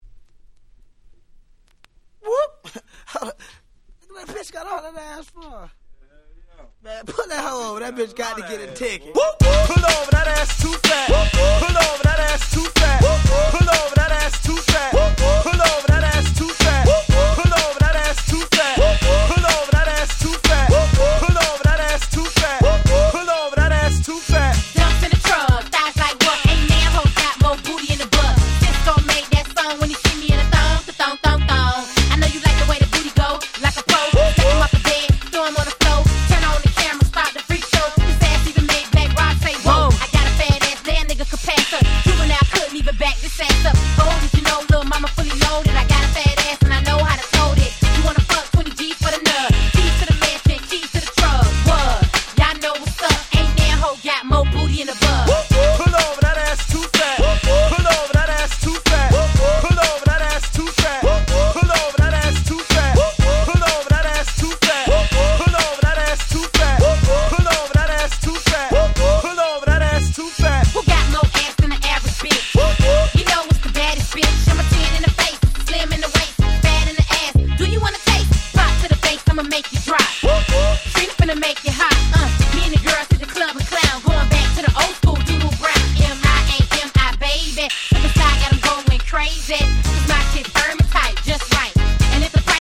00' Southern Hip Hop Classics !!
当時からBrother達にはめちゃくちゃ人気だったイケイケな1曲。